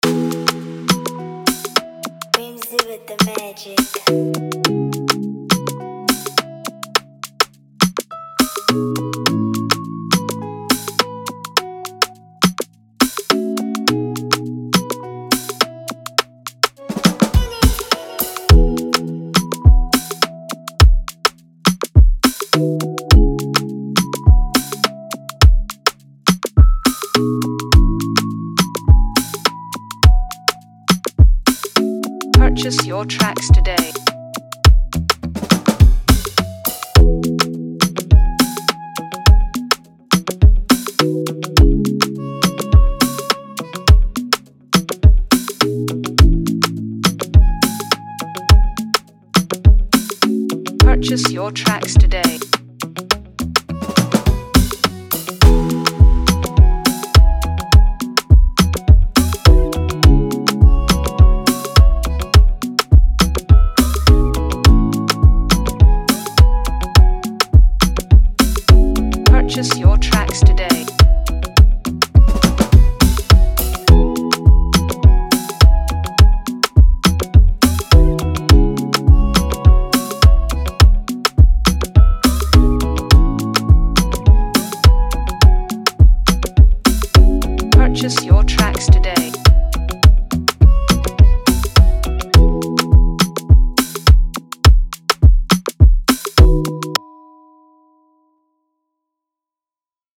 ” an electrifying Afrobeat instrumental